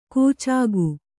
♪ kūcāgu